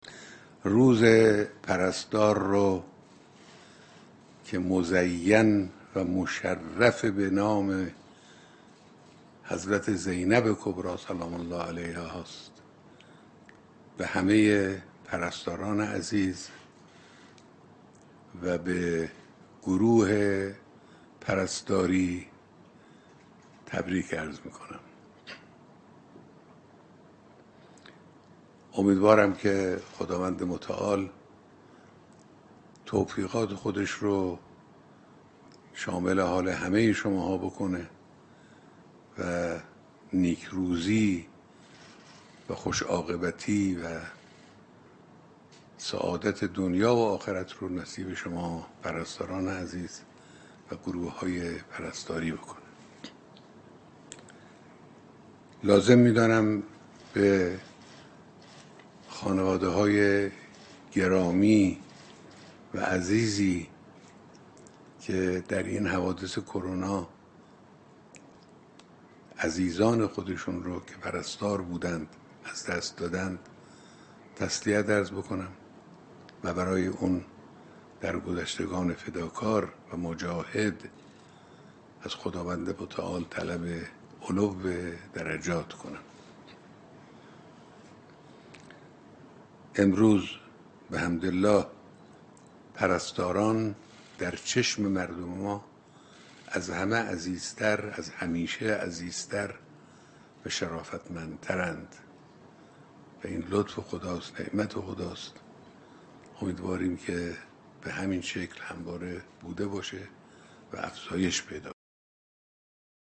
سخنرانی رهبر معظم انقلاب در سال روز ولادت حضرت زینب كبری(س)